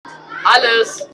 MS Wissenschaft @ Diverse Häfen
Standort war das Wechselnde Häfen in Deutschland.